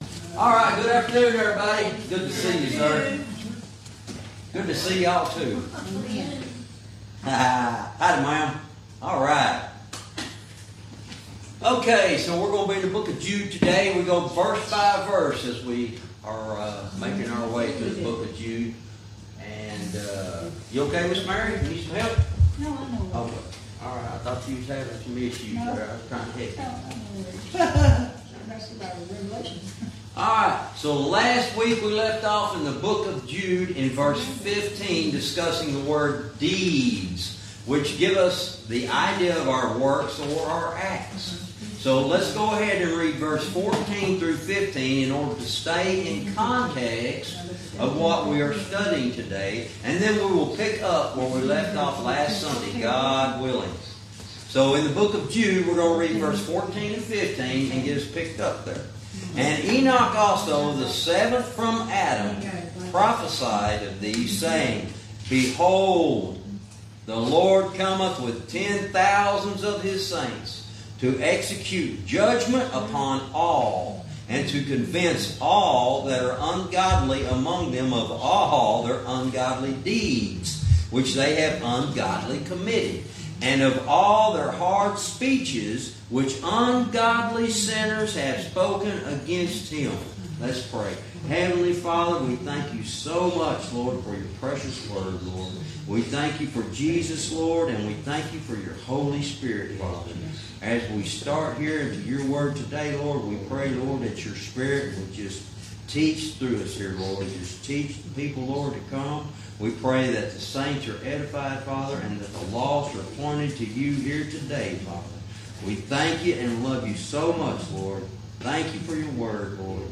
Verse by verse teaching - Jude lesson 68 verse 15